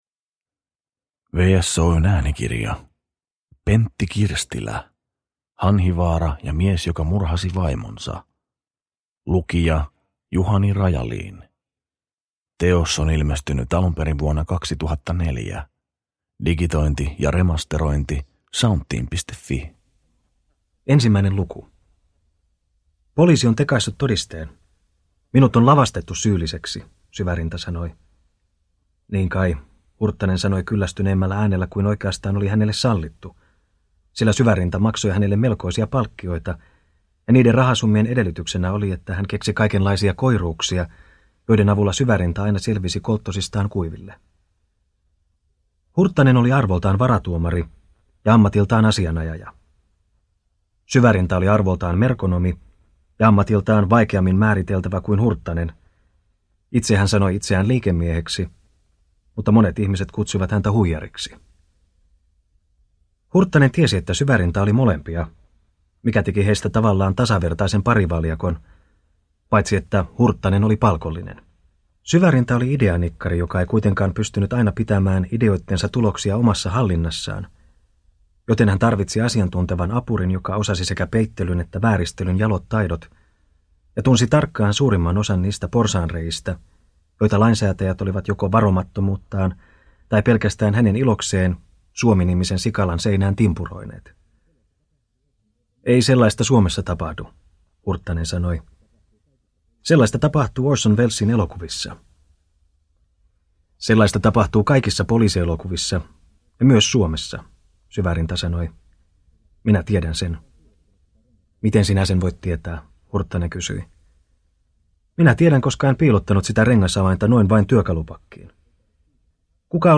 Hanhivaara ja mies joka murhasi vaimonsa – Ljudbok – Laddas ner